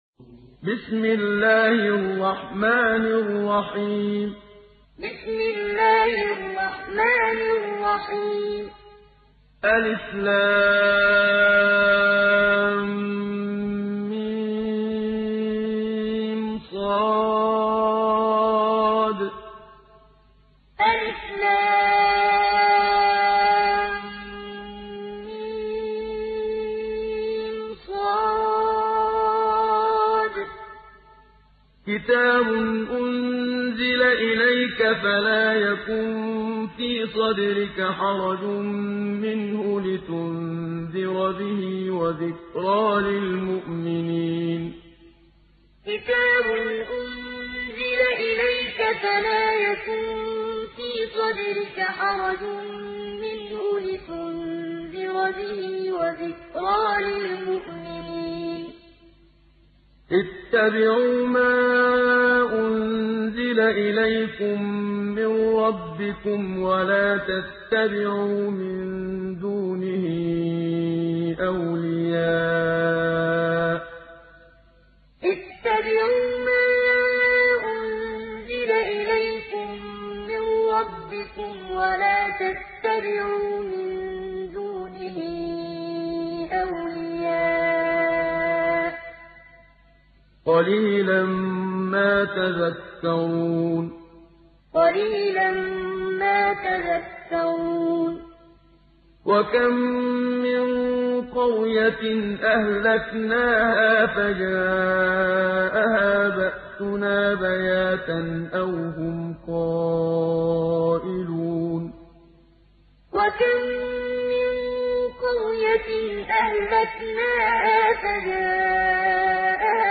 دانلود سوره الأعراف mp3 محمد صديق المنشاوي معلم روایت حفص از عاصم, قرآن را دانلود کنید و گوش کن mp3 ، لینک مستقیم کامل